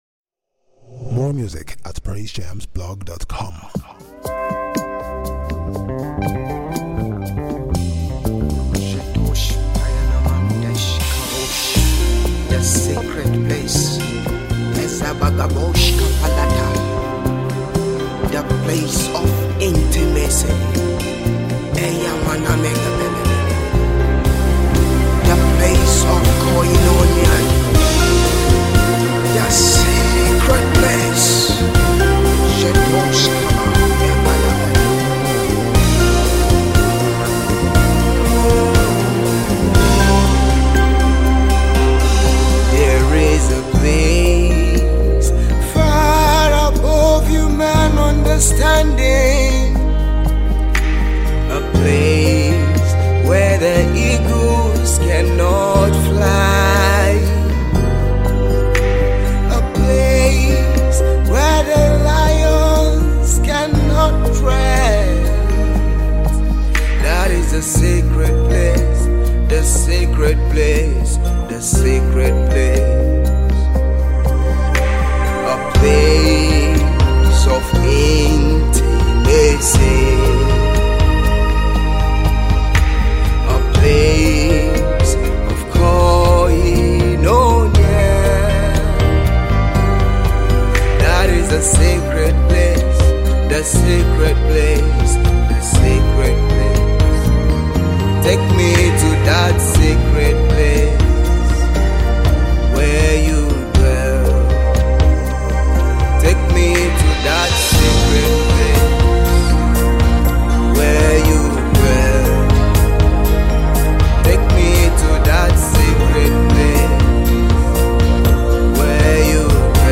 worship song
Nice Gospel